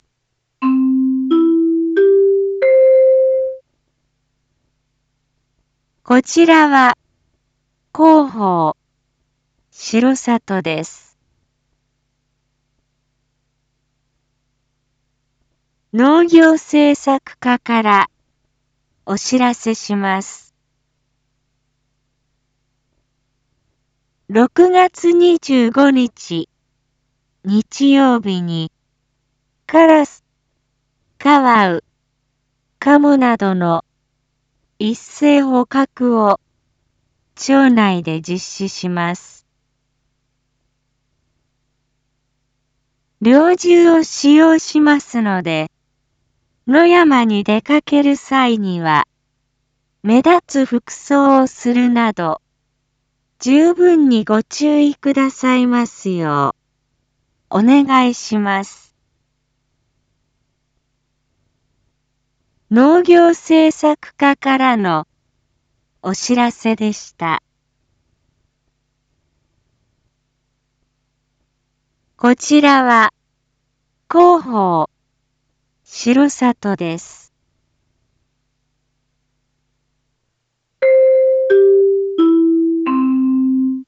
一般放送情報
Back Home 一般放送情報 音声放送 再生 一般放送情報 登録日時：2023-06-24 19:01:25 タイトル：R5.6.24（19時）有害鳥獣 インフォメーション：こちらは広報しろさとです。